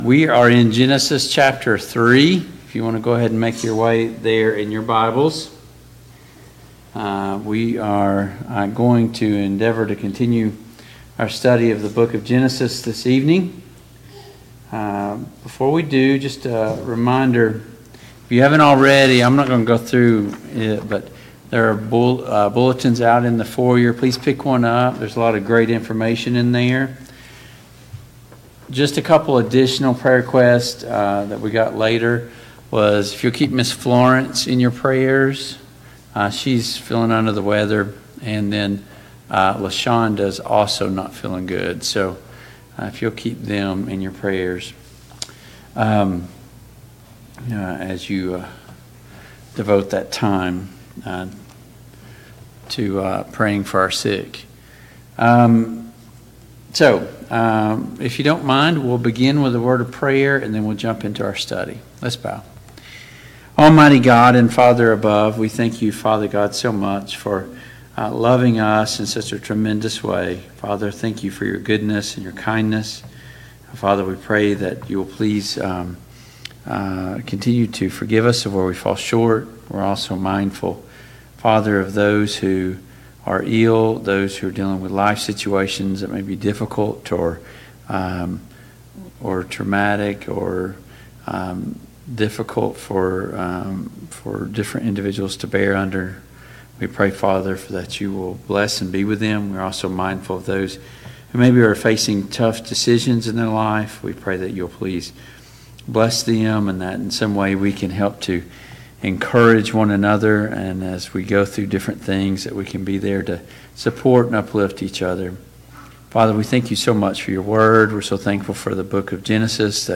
Family Bible Hour Topics: Sin , Temptation , The Fall , The Knowledge of Good and Evil « 4.